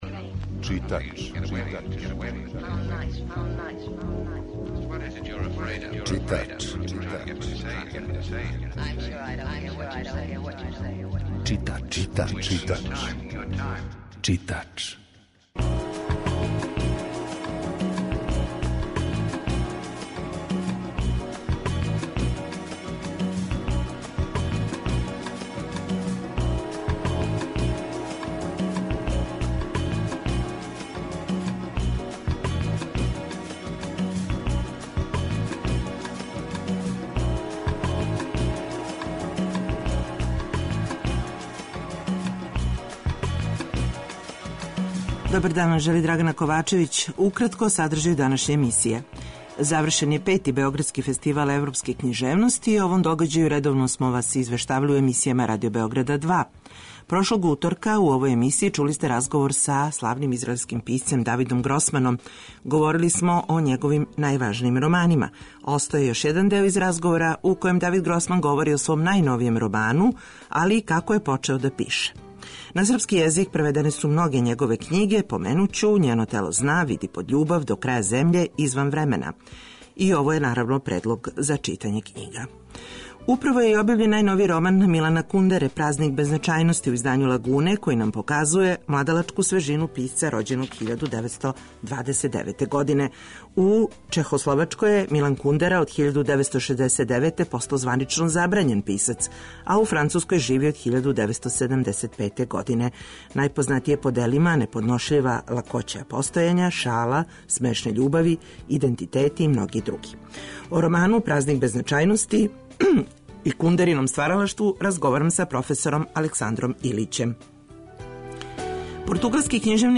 За нама је 5. Београдски фестивал европске књижевности, у прошлој емисији, чули сте разговор са најважнијим гостом фестивала, Давидом Гросманом, данас слушамо још један сегмент из разговора са Гросманом који је везан за почетак писања и најновији роман, који још није преведен на српски језик.
Емисија је колажног типа, али је њена основна концепција – прича о светској књижевности